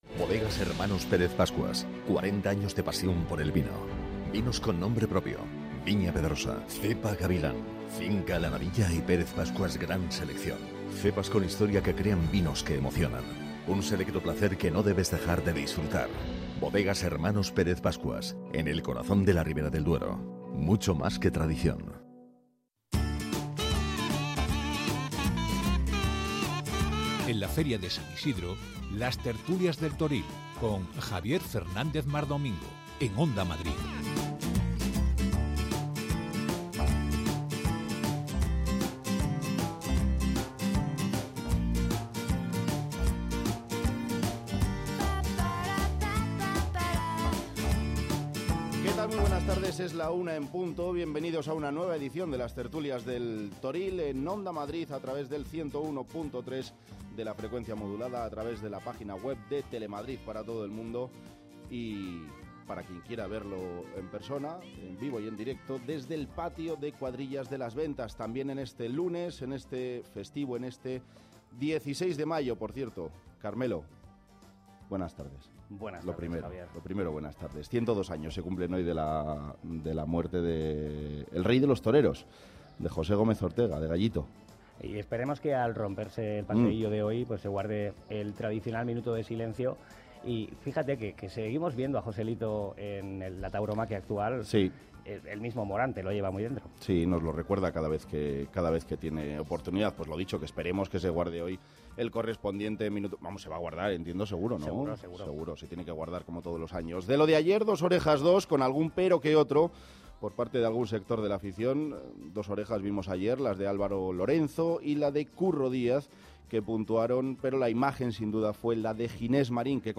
Cada día desde la plaza de toros de las ventas Las Tertulias del Toril en directo